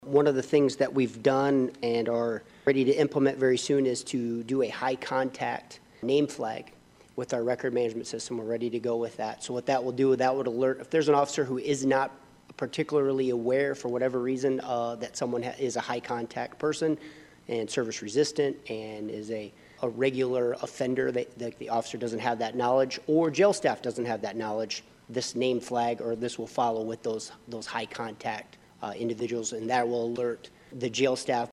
TOLD CITY COUNCIL MEMBERS